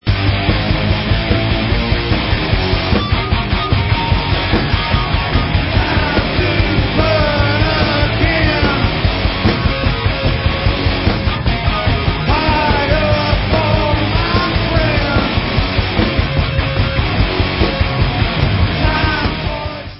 live 2001